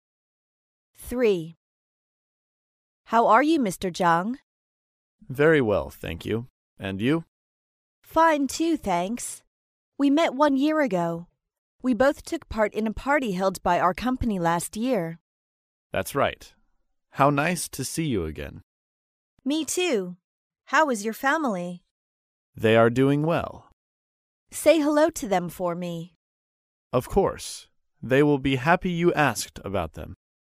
在线英语听力室高频英语口语对话 第3期:同事见面的听力文件下载,《高频英语口语对话》栏目包含了日常生活中经常使用的英语情景对话，是学习英语口语，能够帮助英语爱好者在听英语对话的过程中，积累英语口语习语知识，提高英语听说水平，并通过栏目中的中英文字幕和音频MP3文件，提高英语语感。